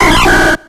QWILFISH.ogg